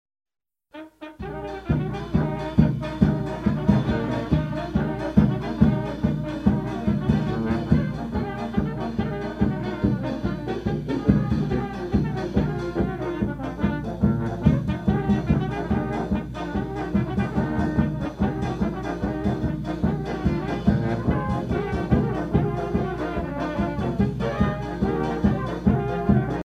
Usage d'après l'analyste gestuel : danse ;